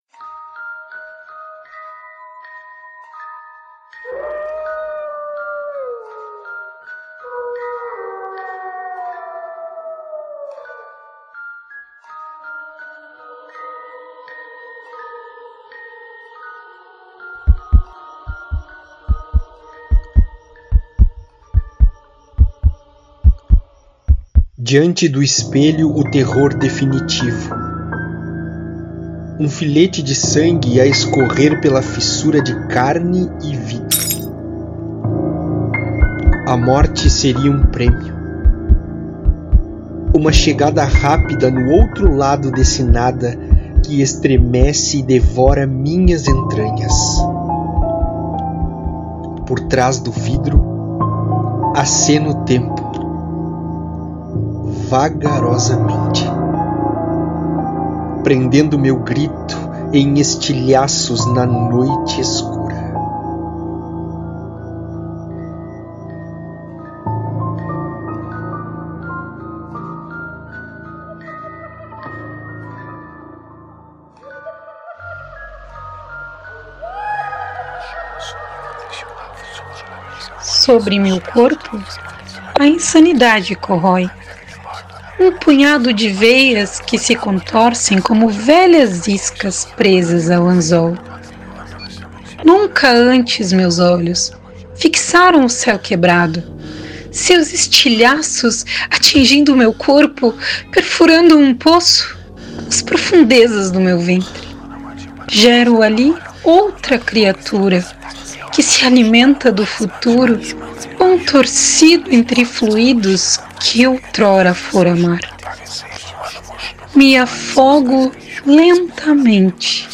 Projeto divulga poesias de terror neste Dia das Bruxas